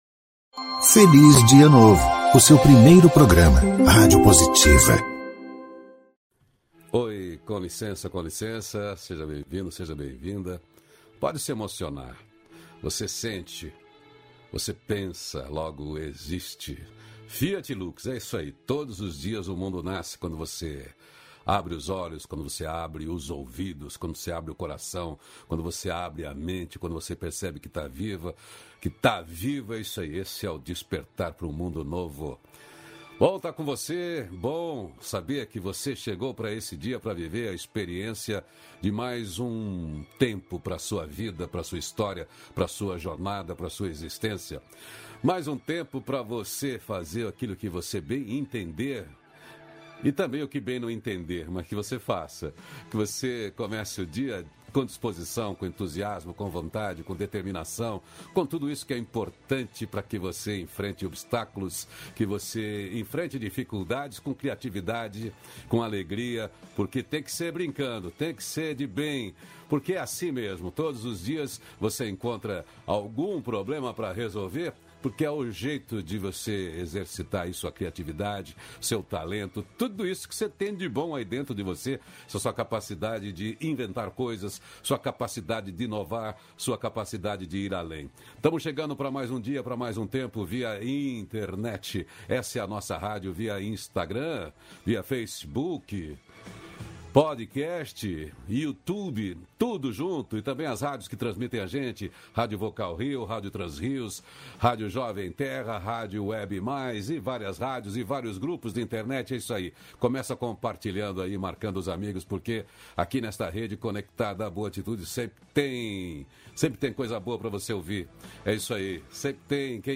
-556FelizDiaNovo-Entrevista.mp3